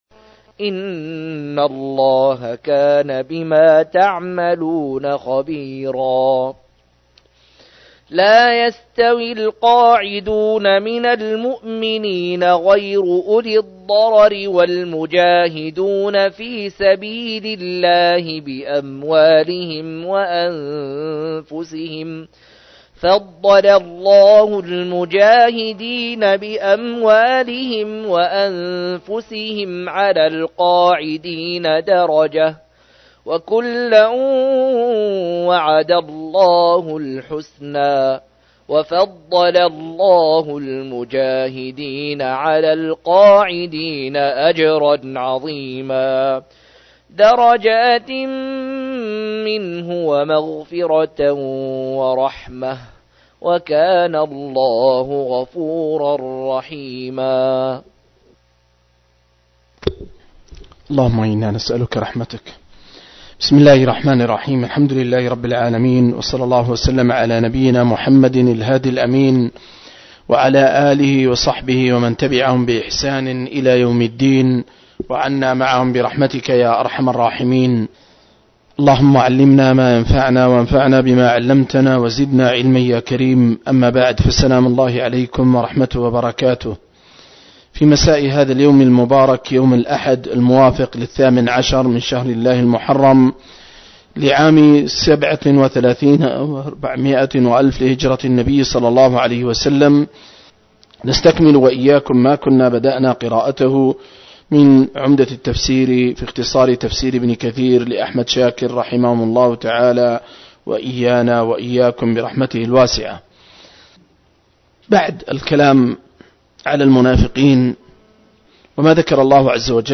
096- عمدة التفسير عن الحافظ ابن كثير رحمه الله للعلامة أحمد شاكر رحمه الله – قراءة وتعليق –